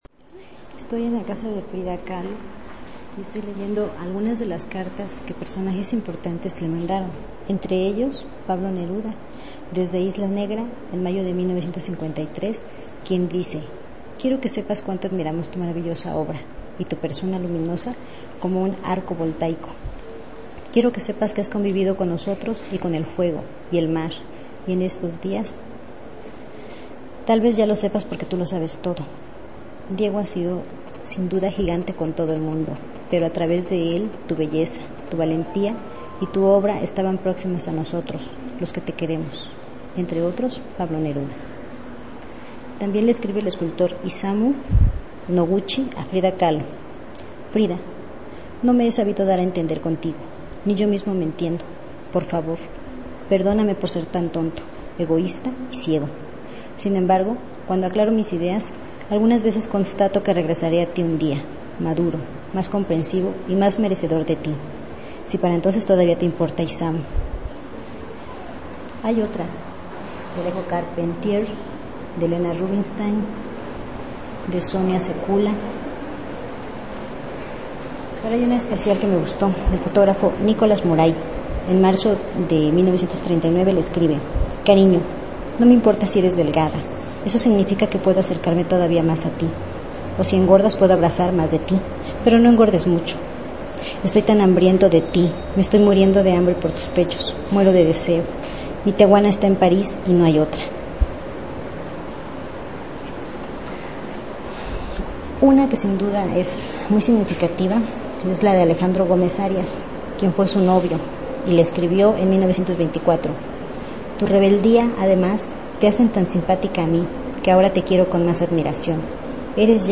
Lugar: Museo Frida Kahlo, Coyoacán; Ciudad de Mexico.
Equipo: Minidisc NetMD MD-N707, micrófono de construcción casera (más info)